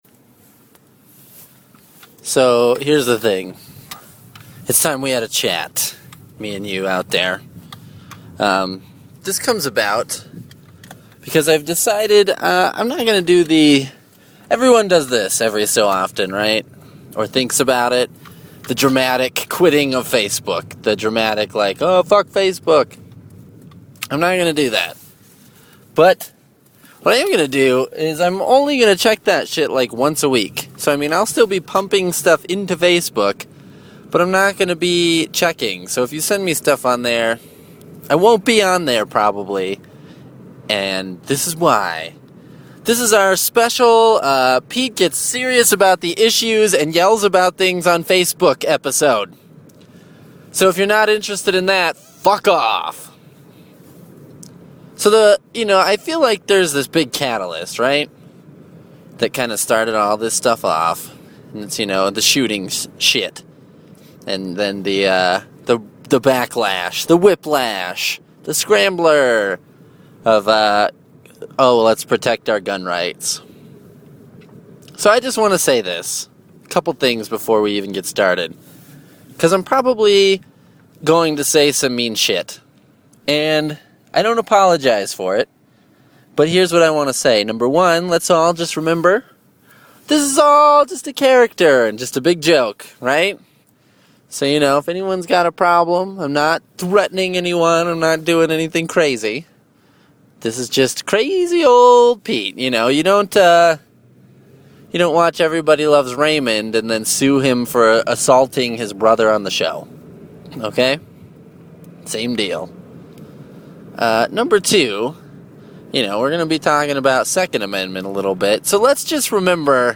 This entire episode is just me yelling about things I don’t like on Facebook.